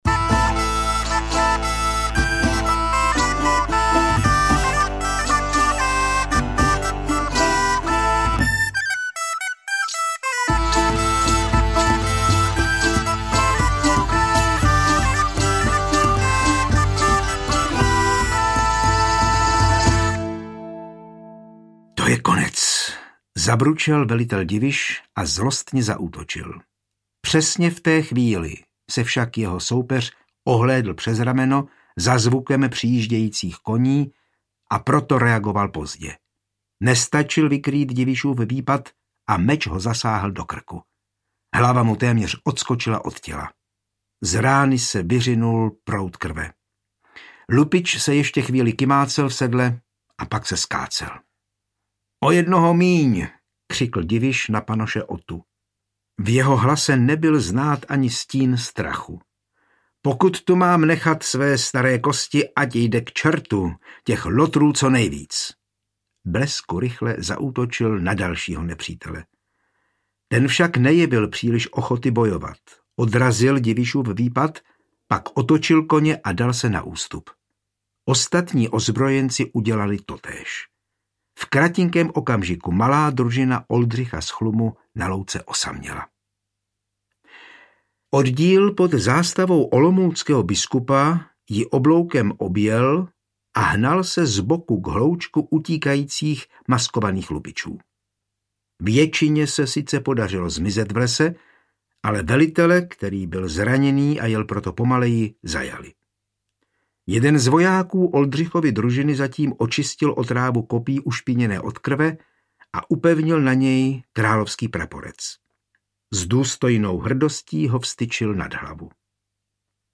Prokletí brněnských řeholníků audiokniha
Ukázka z knihy
• InterpretJan Hyhlík